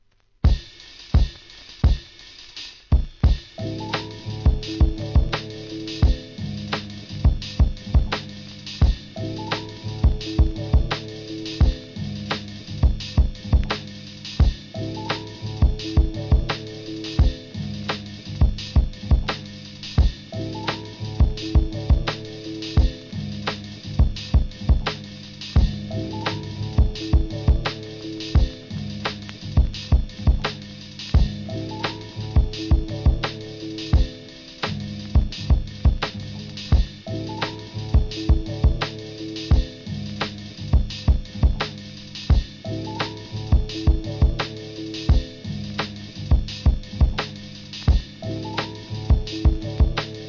UK産ブレイクビーツ!!